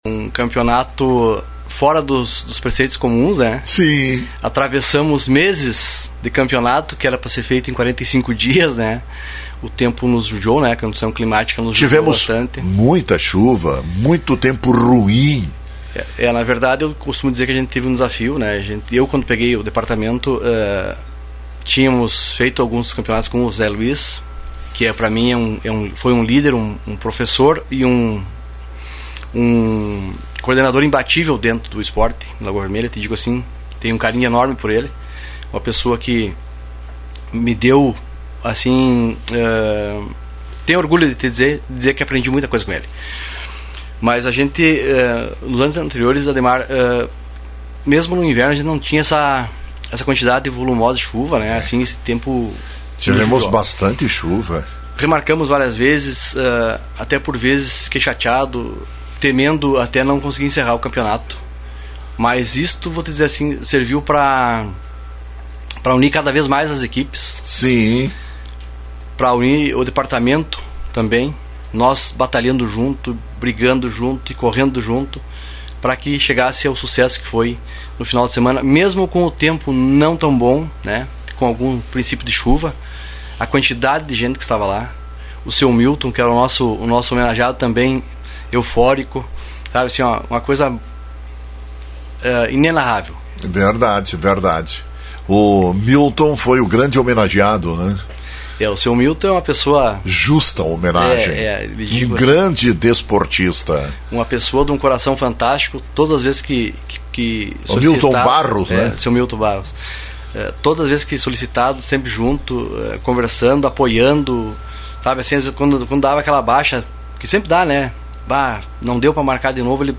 Participou do programa Bom Dia Cidade desta terça-feira.